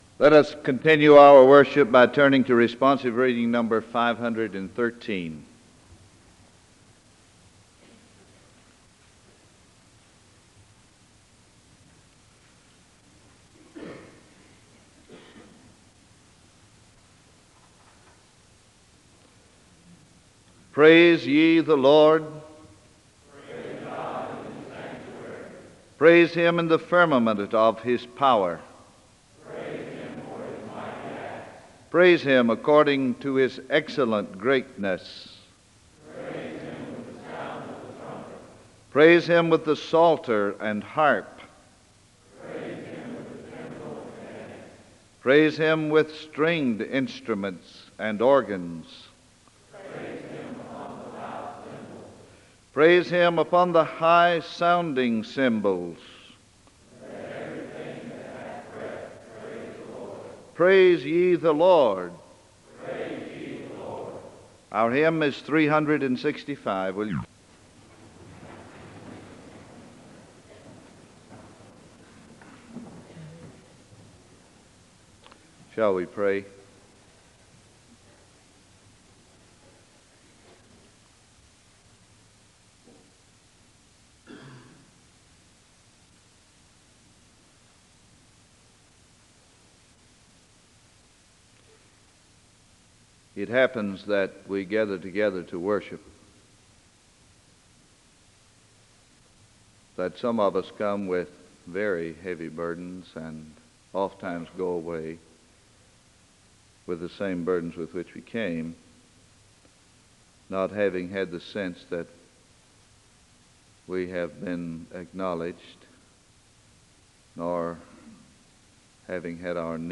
The service begins with a responsive reading (00:00-01:10). The speaker gives a word of prayer (01:11-05:10).
The choir sings a song of worship (07:01-11:21).
SEBTS Chapel and Special Event Recordings SEBTS Chapel and Special Event Recordings